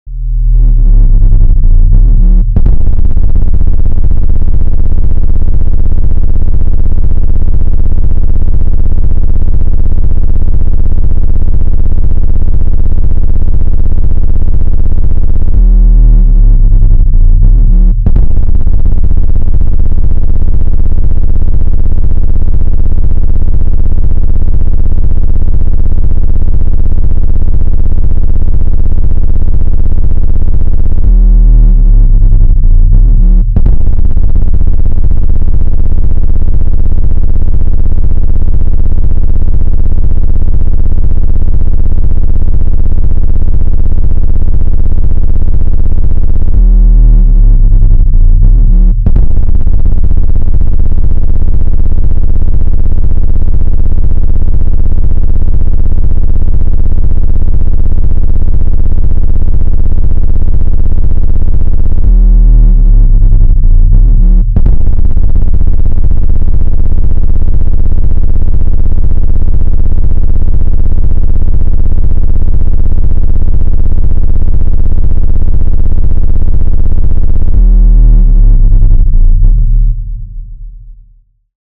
Аудиофайлы генерируют специфические частоты, которые помогают вытолкнуть загрязнения без механического вмешательства.
Звуки для очистки динамиков от пыли: Быстрая чистка динамика от пыли (самый мощный способ)